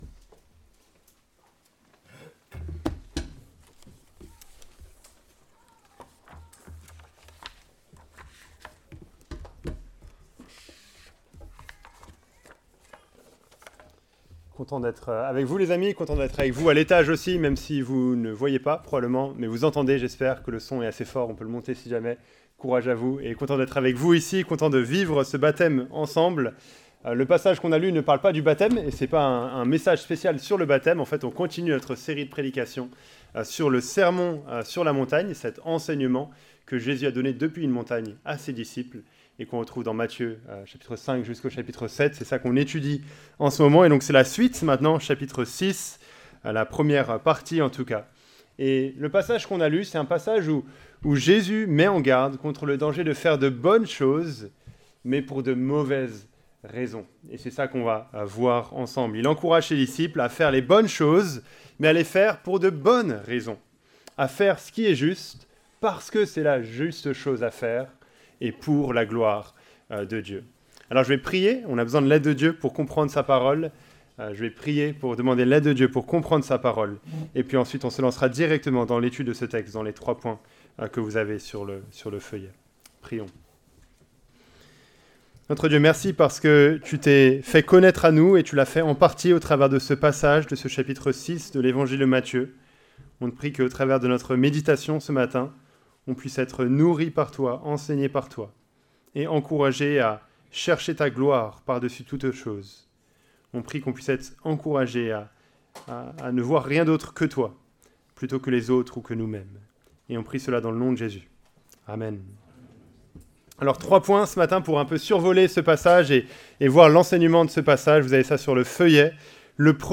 Predication-du-7-juillet-2024.mp3